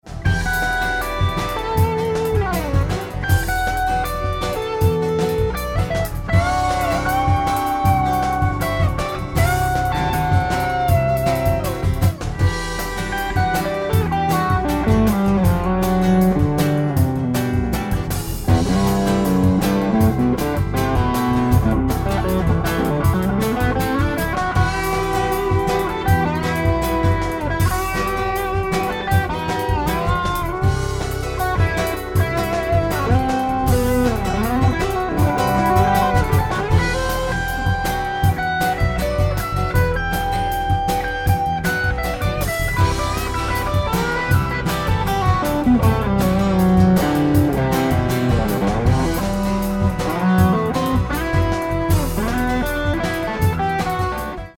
In this example you can listen to a track both in a acoustically untreated- and treated room.